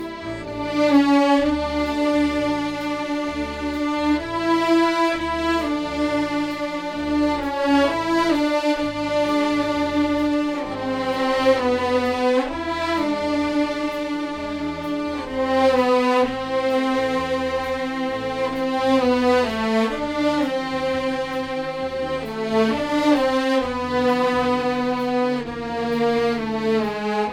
Чем "размочить" живые струнные?
взял на себя смелость быстро разбросать по панораме кусок.
Это примерно то, о чем с начала темы все говорят) Вложения скрипки радиатор.mp3 скрипки радиатор.mp3 1 MB · Просмотры: 377 task2.mp3 task2.mp3 2,7 MB · Просмотры: 381